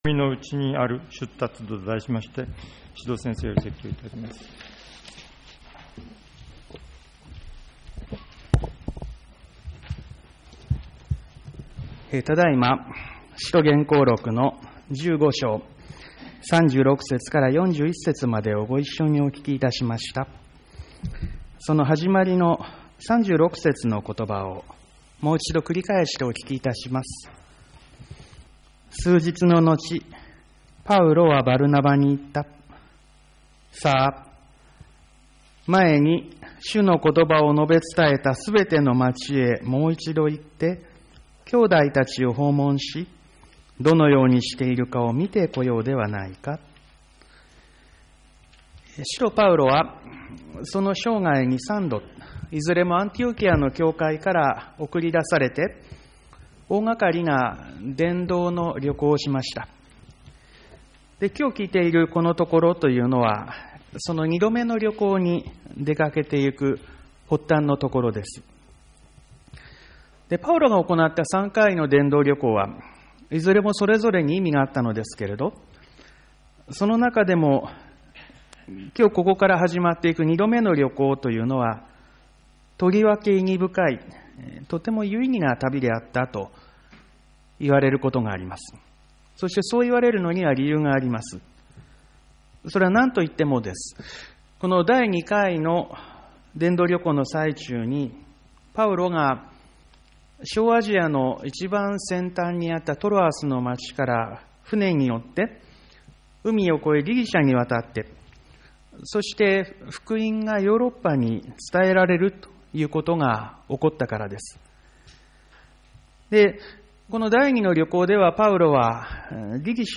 ■ ■ ■ ■ ■ ■ ■ ■ ■ 2020年5月 5月3日 5月10日 5月17日 5月24日 5月31日 毎週日曜日の礼拝で語られる説教（聖書の説き明かし）の要旨をUPしています。